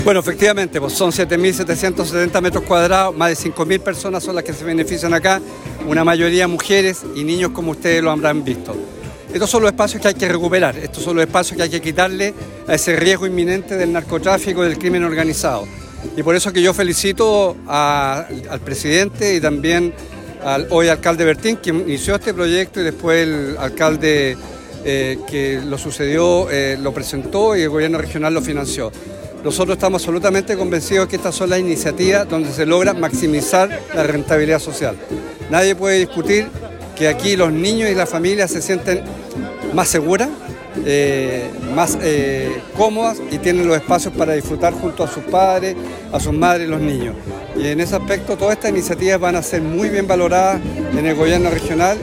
Este viernes, con la presencia de autoridades nacionales, regionales, locales y vecinos del sector, se llevó a cabo la inauguración de la nueva Plaza Los Aromos en Osorno, ubicada en el sector oriente de la ciudad.
El Gobernador Regional, Alejandro Santana, destacó que este nuevo espacio será un beneficio para cientos de familias, ya que no solo proporcionará un lugar de recreo, sino que también contribuirá a maximizar la rentabilidad social, mejorando la calidad de vida de los residentes del sector.
18-enero-24-alejandro-santana-inauguracion.mp3